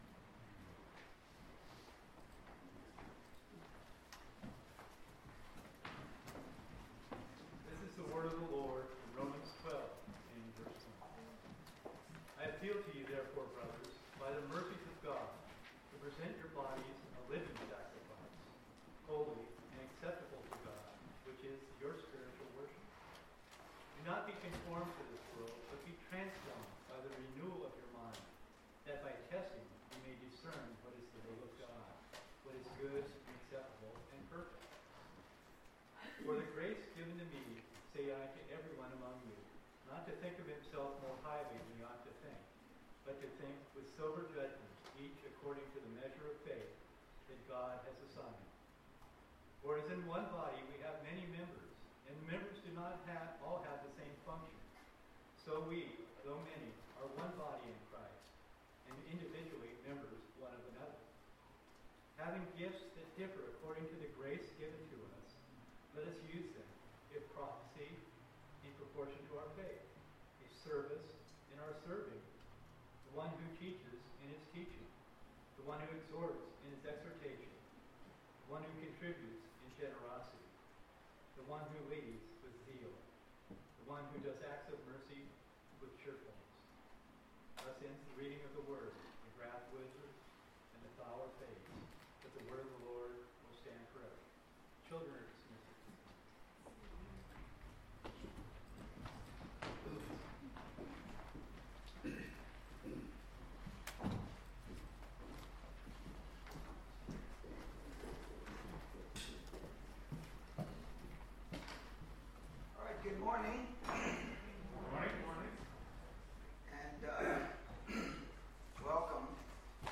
Passage: Romans 12:1-8 Service Type: Sunday Morning